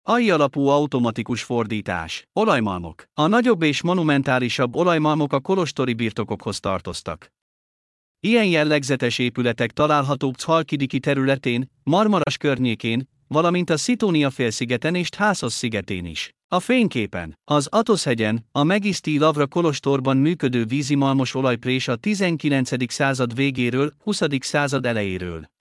Hangalapú idegenvezetés